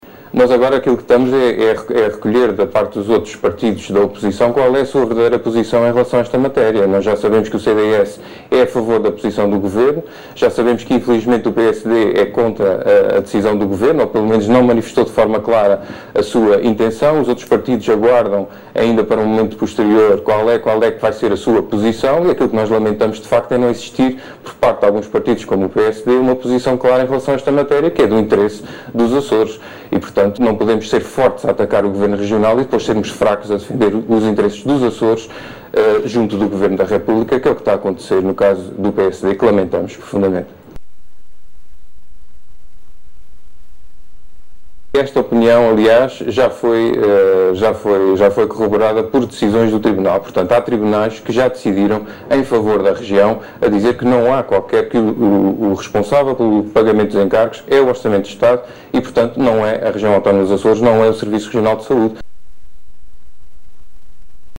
Em declarações ao programa “Açores Hoje”, a propósito da alegada dívida ao Serviço Nacional de Saúde, Miguel Correia disse que é importante que os partidos nos Açores definam, de forma clara, a sua posição sobre esta matéria.